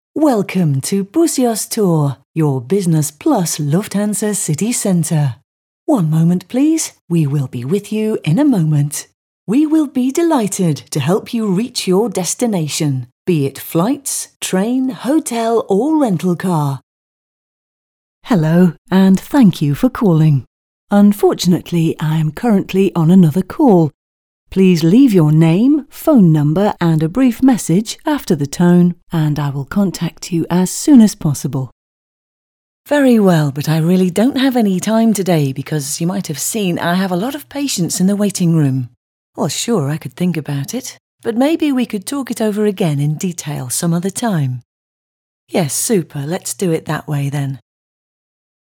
Englisch (UK)
Weiblich